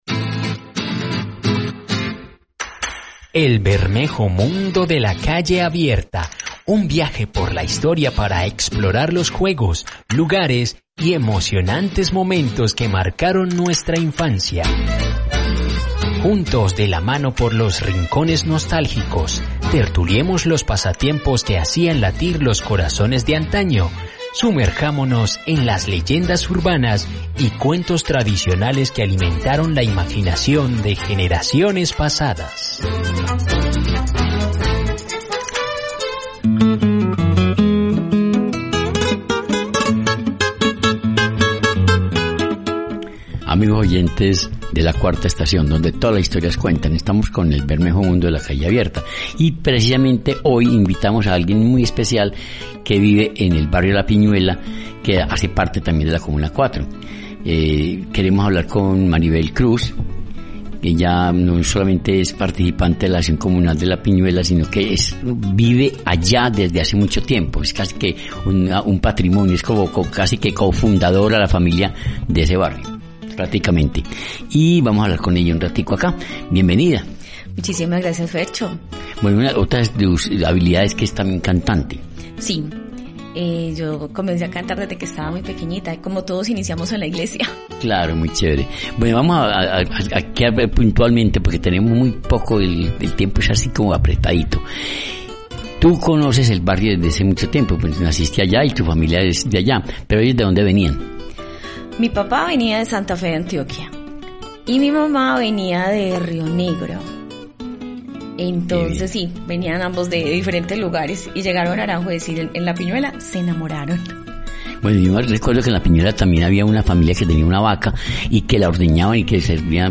estuvo en nuestra Eco-Cabina, contándonos sobre la historia del barrio y la Junta de Acción Comunal de La Piñuela.